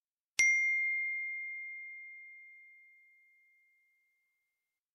Звуки уведомлений WhatsApp
Стандартный звук уведомления WhatsApp на iPhone короткая заметка